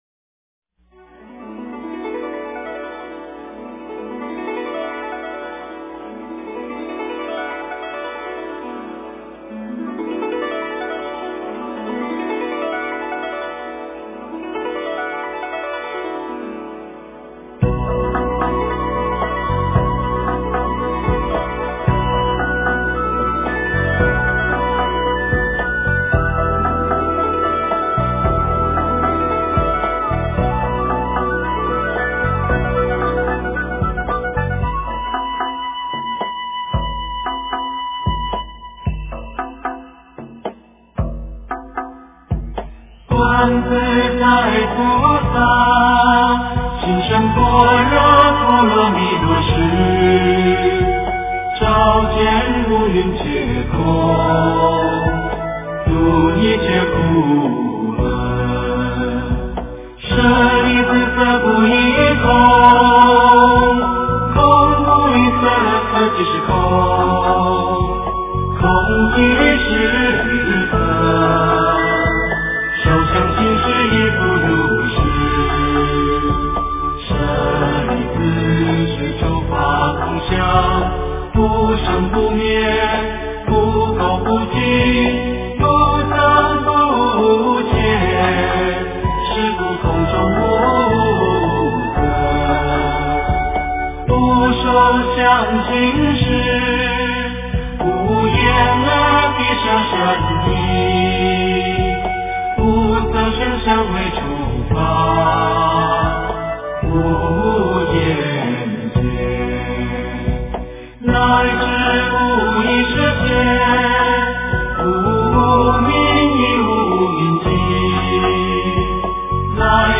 心经 诵经 心经--达摩配乐 点我： 标签: 佛音 诵经 佛教音乐 返回列表 上一篇： 极乐净土 下一篇： 放下难舍的缘 相关文章 绿度母心咒 -梵唱--般禅梵唱妙音组 绿度母心咒 -梵唱--般禅梵唱妙音组...